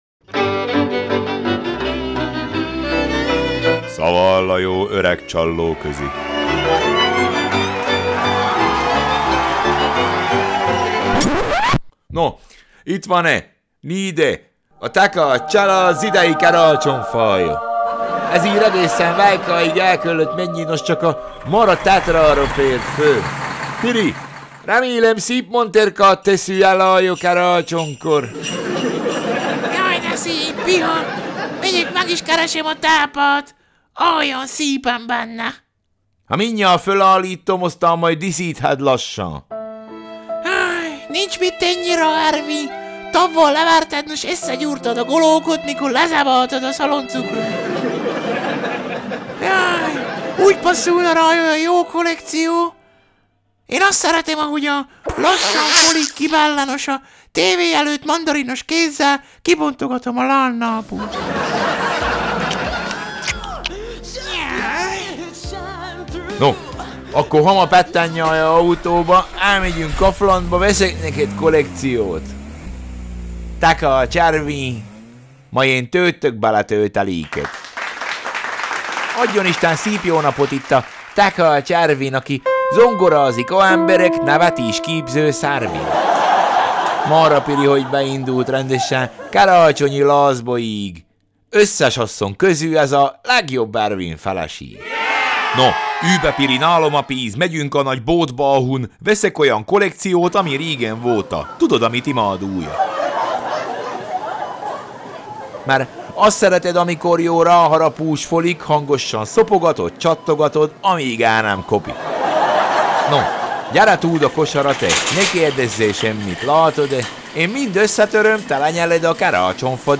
Szaval a jó öreg csallóközi